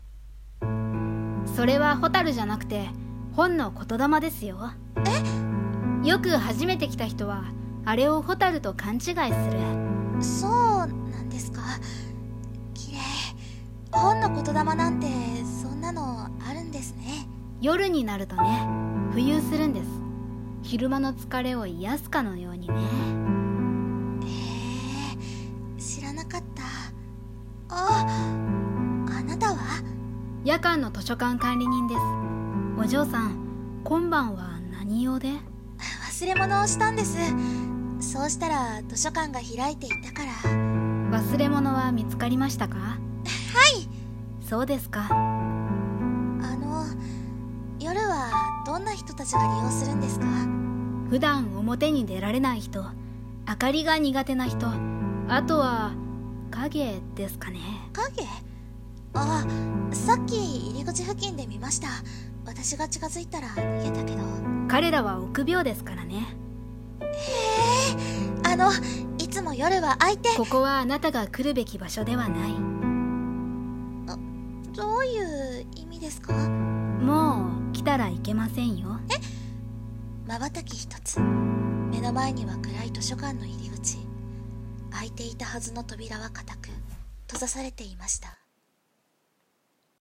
【掛け合い】
【声劇台本】